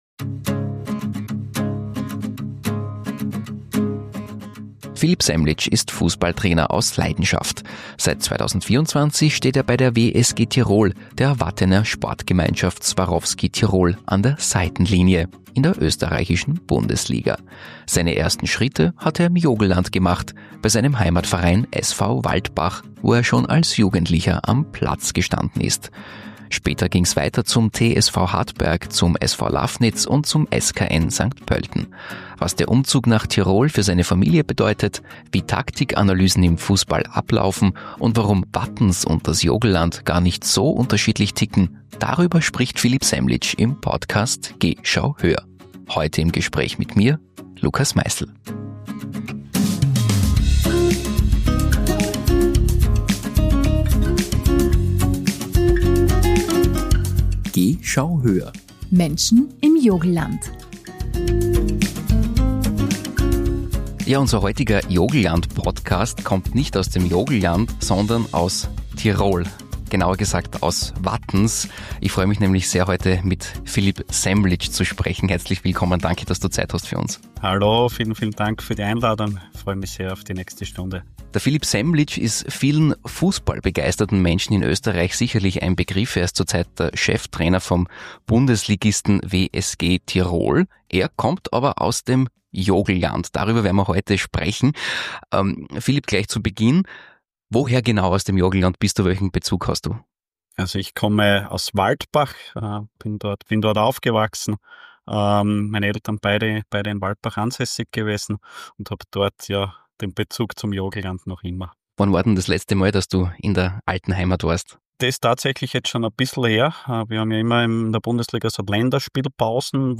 Philipp Semlic aus Waldbach ist Cheftrainer des Bundesligisten WSG Tirol. Im Gespräch erzählt er von seinem Werdegang, vom Sportlehrer zum Profitrainer, vom Alltag an der Seitenlinie und von den Herausforderungen in einer Liga, in der kleine Vereine gegen finanzstarke Klubs bestehen müssen.